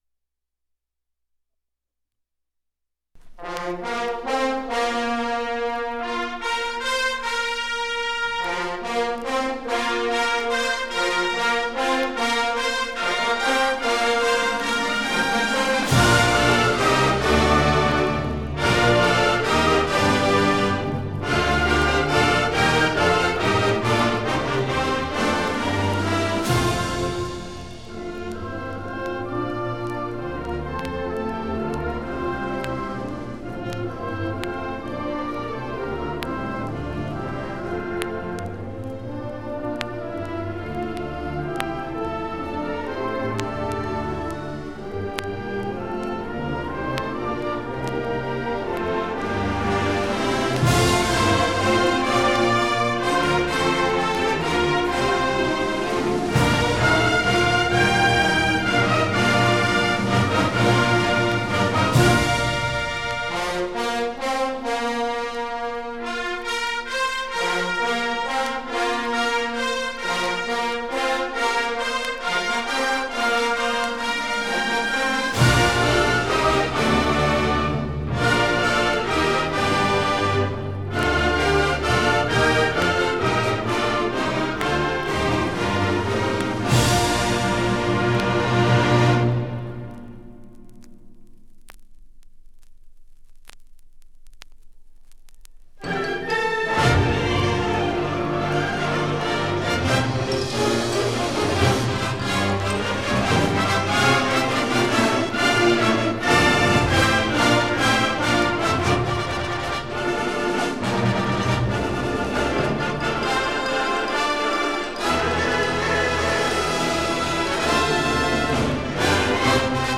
1982 Music in May band and orchestra performance recording · Digital Exhibits · heritage
It brings outstanding high school music students together on the university campus for several days of lessons and events, culminating in the final concert that this recording preserves.